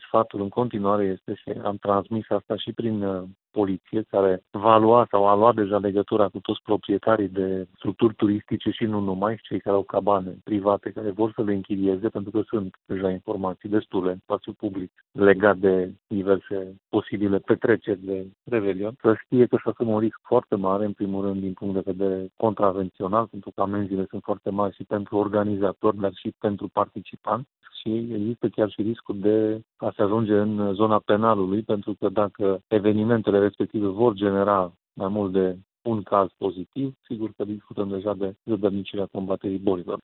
Sunt deja informații publice privind organizarea de petreceri de Revelion, de aceea poliția a luat deja sau va lua legătura cu proprietarii de cabane, spune prefectul Mircea Abrudean. Aceste chestiuni sunt serioase, iar cei care organizează astfel de petreceri sunt pasibili de a se alege cu dosar penal: